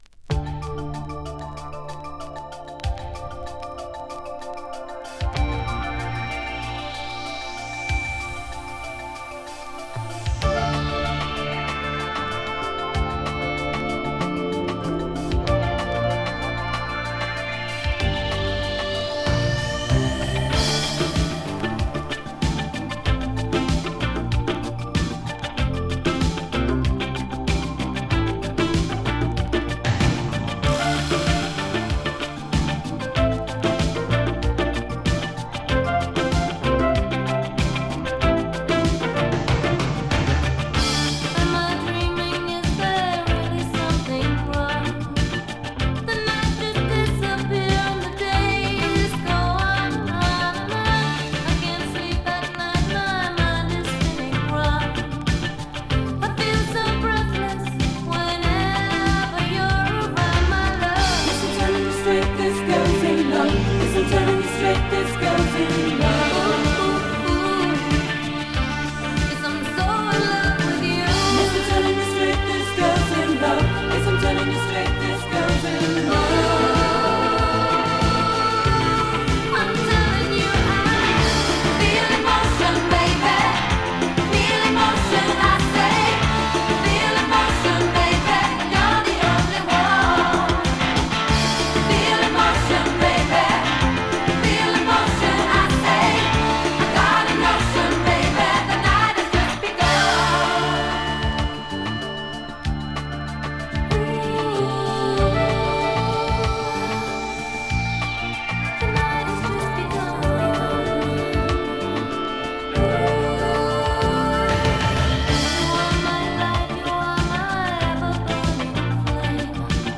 [UK] [BOOGIE]
A melancholy boogie from the UK!
There is a slight dust noise due to thin scratches.